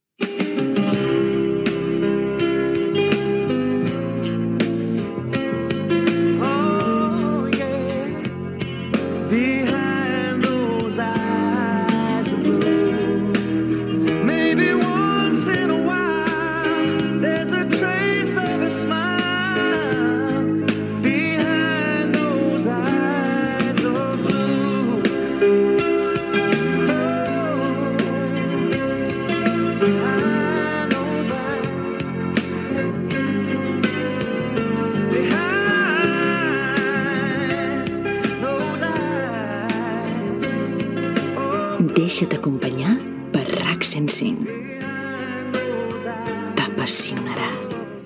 a11507a4336f66c58468a4df7e8e2782eb2506a4.mp3 Títol Ràdio Associació RAC 105 Emissora Ràdio Associació RAC 105 Titularitat Pública nacional Descripció Identificació de l'emissora.